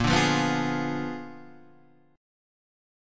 A#7b9 chord